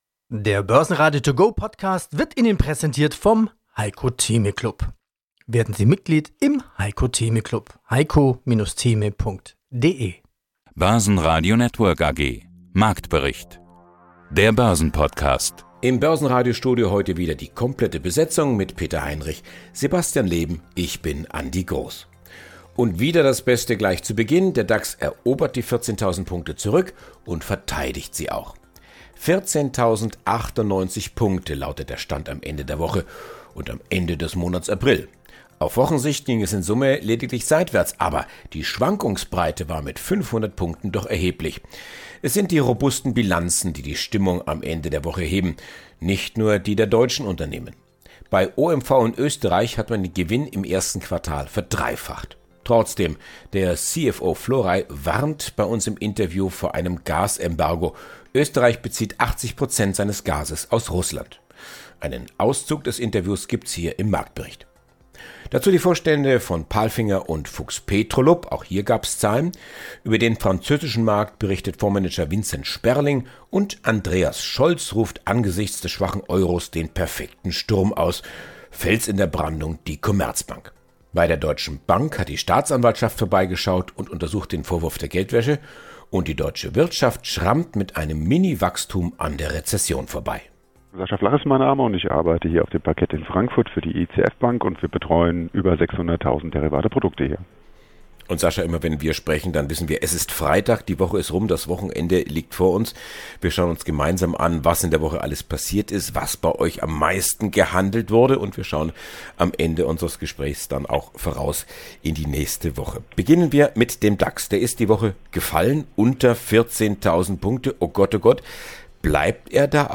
(Einen Auszug des Interviews gibt’s hier im Marktbericht).